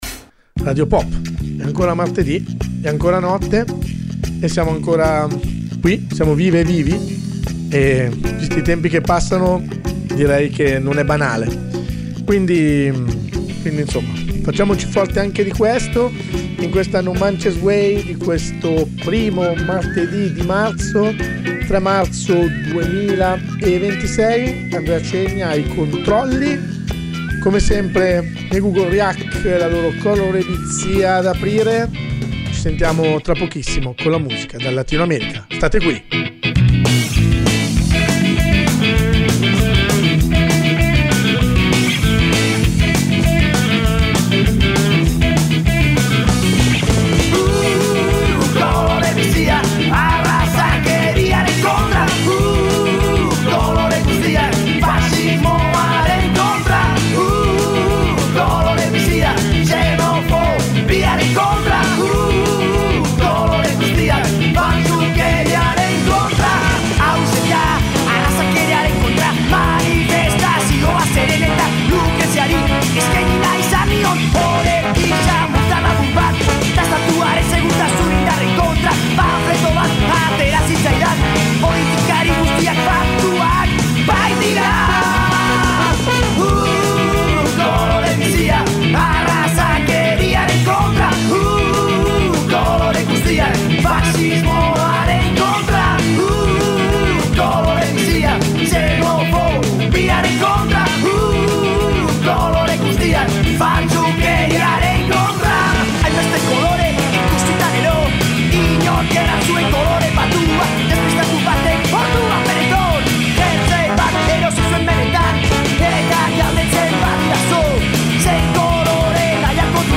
Un viaggio musicale dentro le culture latino americane.